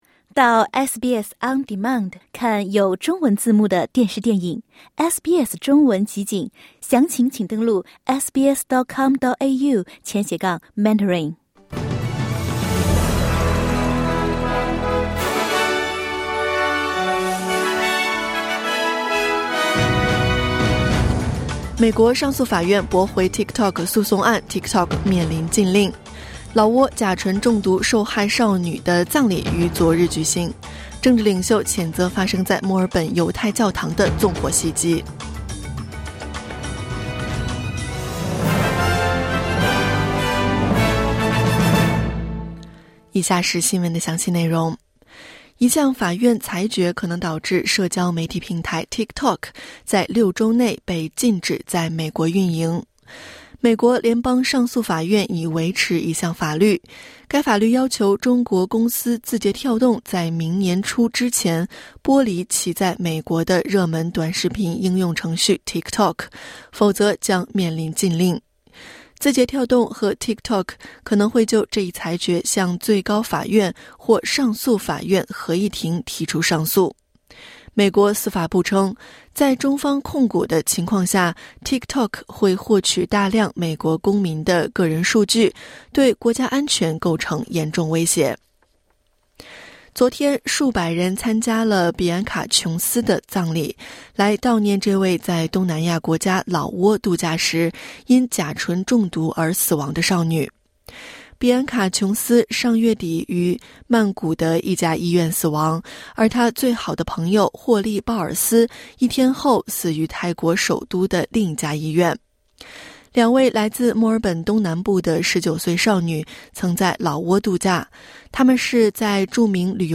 SBS Mandarin morning news Source: Getty / Getty Images